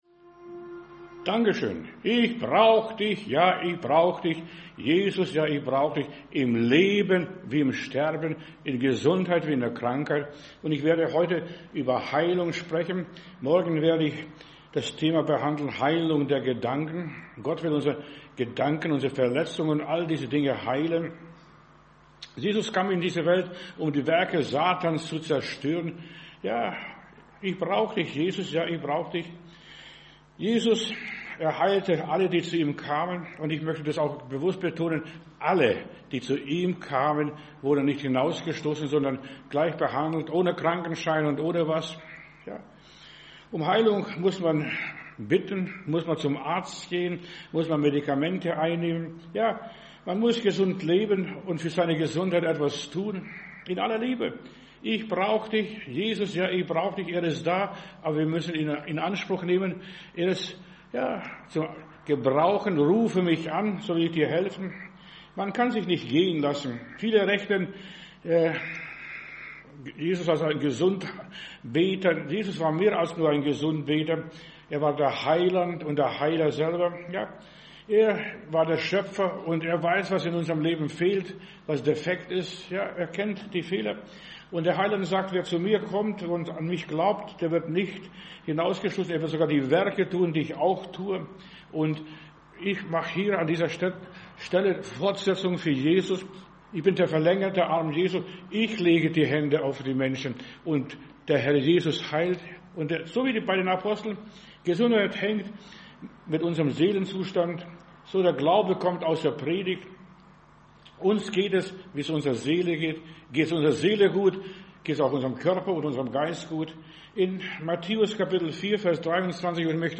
Predigt herunterladen: Audio 2025-05-10 Es gibt Heilung Video Es gibt Heilung